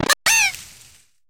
Cri de Matourgeon dans Pokémon HOME.